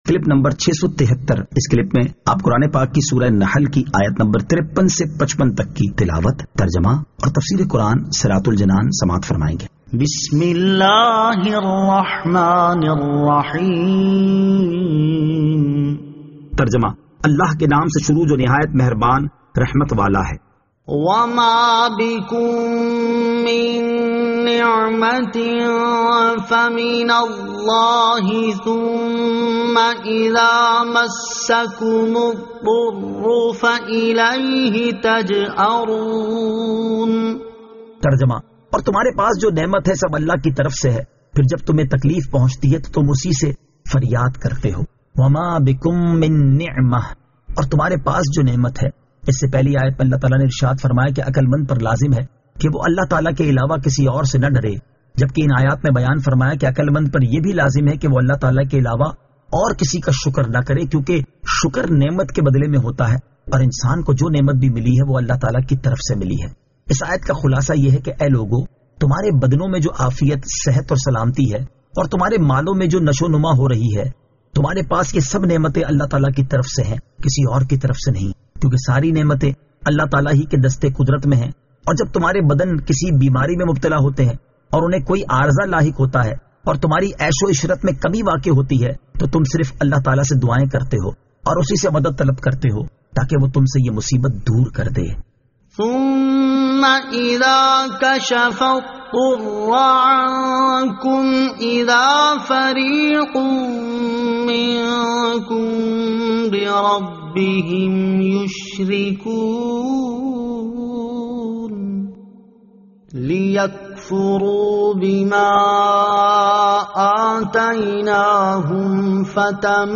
Surah An-Nahl Ayat 53 To 55 Tilawat , Tarjama , Tafseer